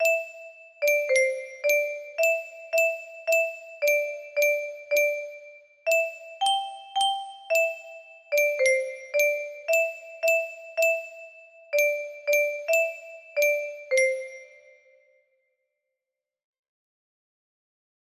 plain music box melody